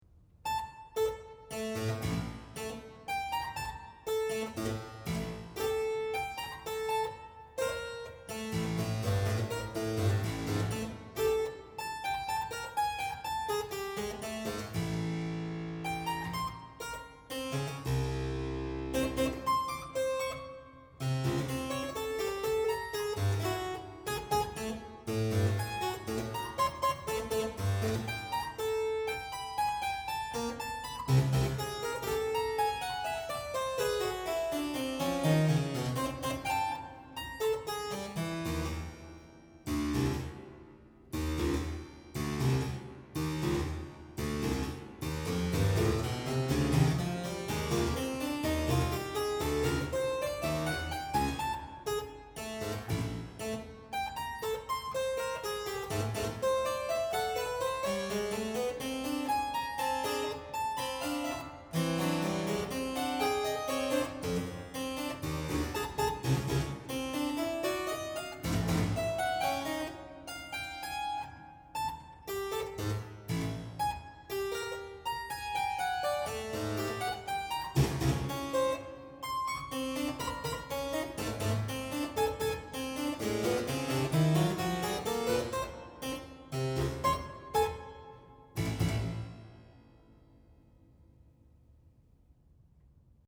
voor clavecimbel solo